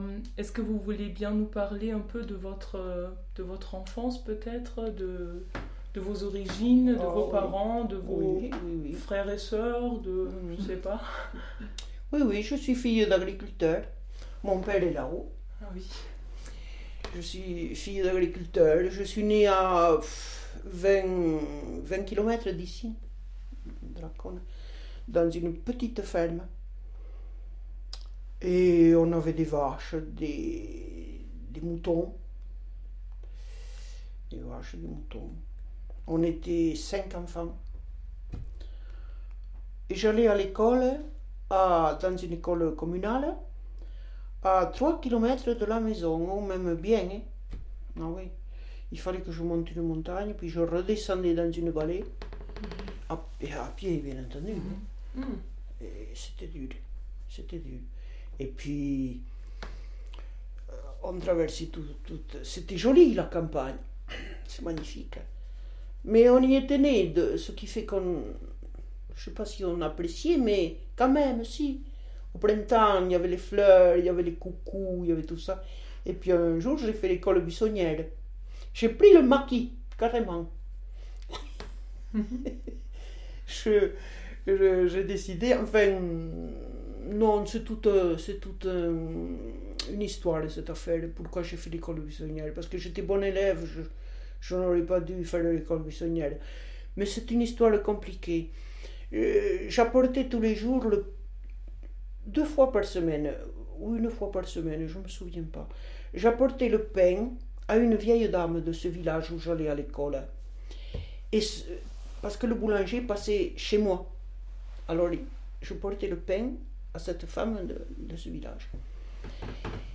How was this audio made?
B_PIII_ch3_WAV_Lacaune_Tarn_Merid.mp3